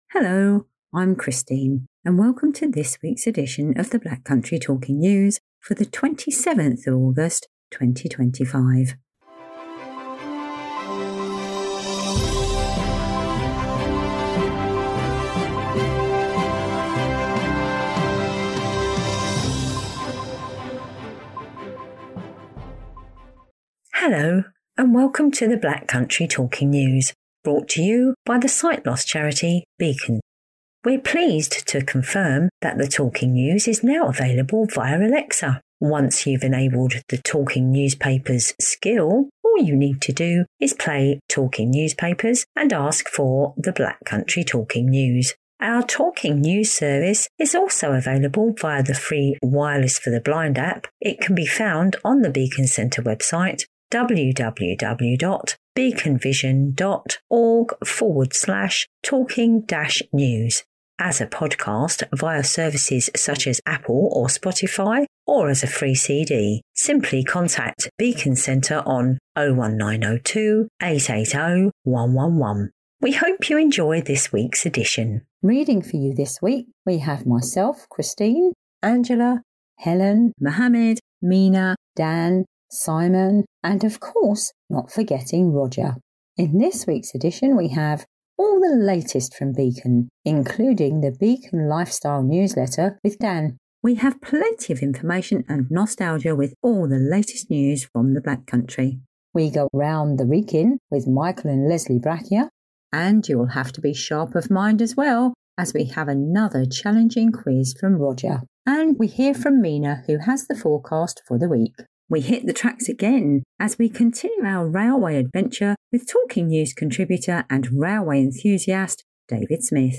Black Country Talking News 27th August 2025
our exclusive drama series